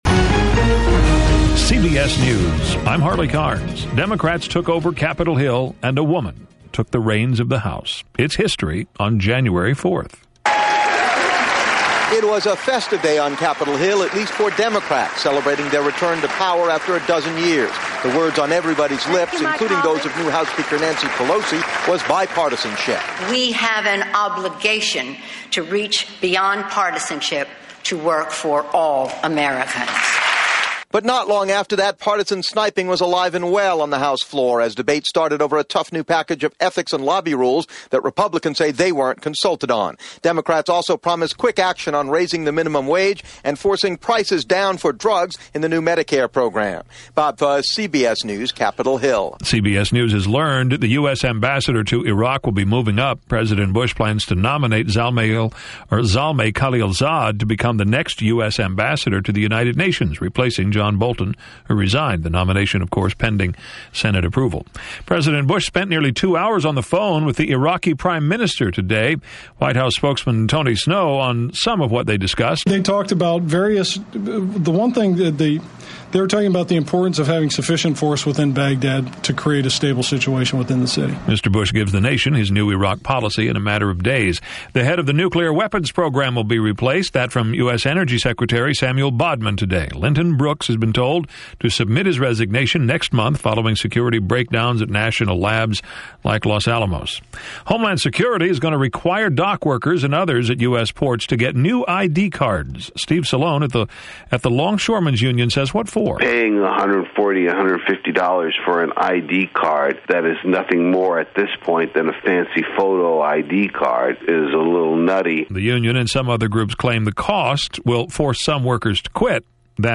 And that’s a little of what went on this January 4th 2007 – only 10 years ago, via CBS Radio Hourly News.